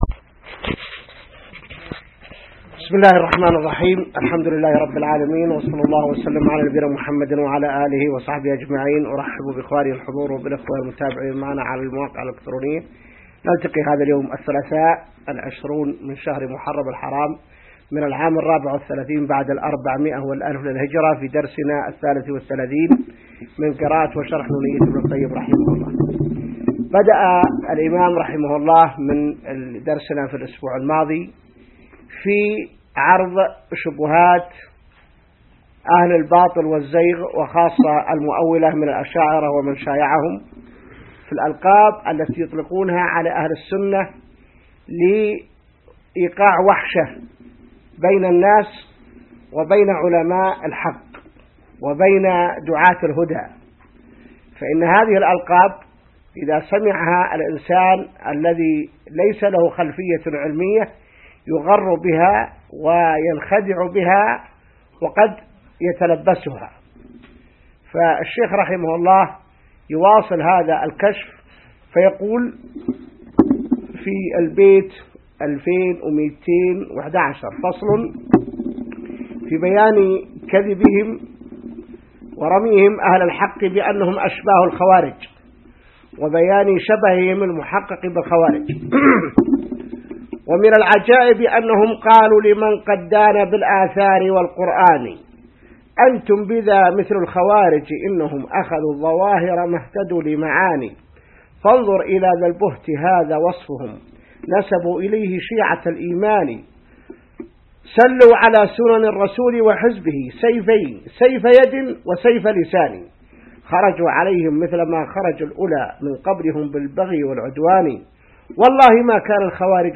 الدرس 33 من شرح نونية ابن القيم | موقع المسلم